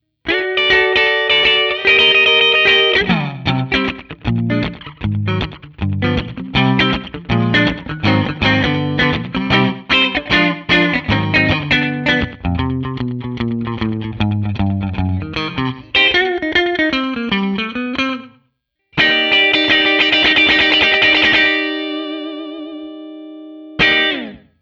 The middle position is magic.